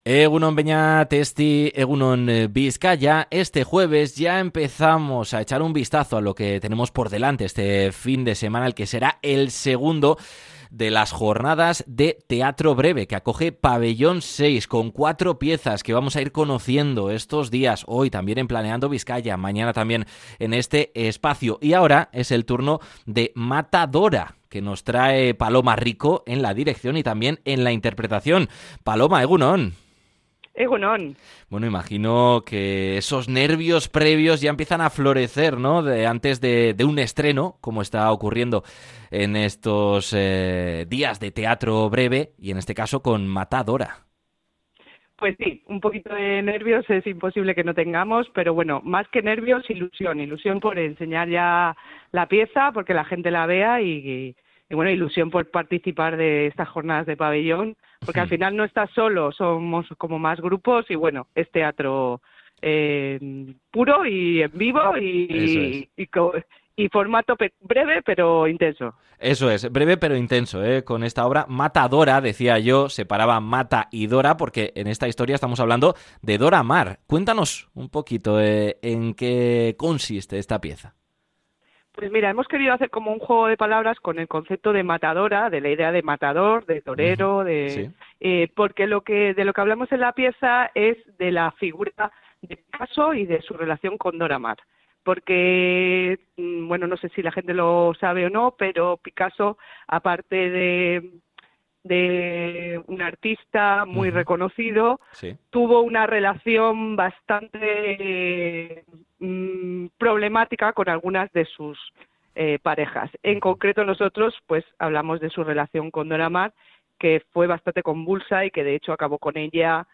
Podcast Cultura